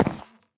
wood2.ogg